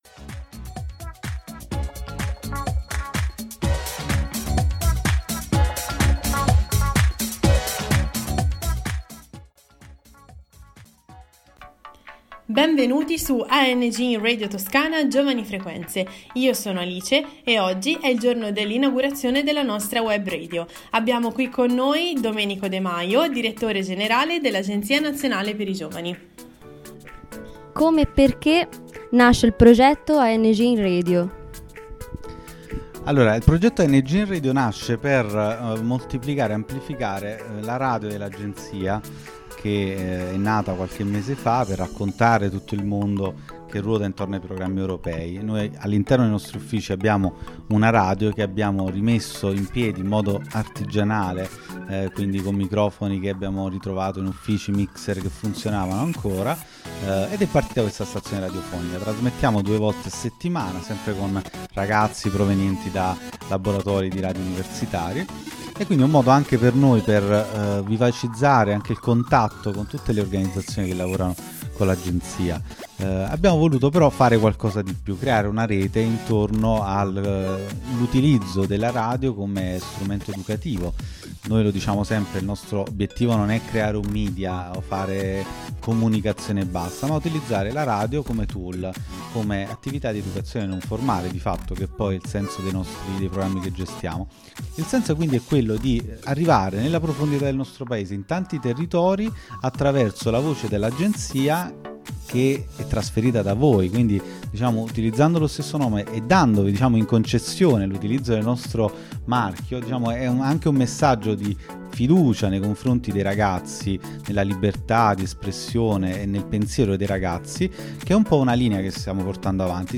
Il podcast è stato registrato in occasione dell’inaugurazione della nostra web radio. Il direttore di Ang, Domenico De Maio, ha incontrato i giovani appartenenti alla nostra realtà per presentare i progetti dell’agenzia e ascoltare la loro esperienza.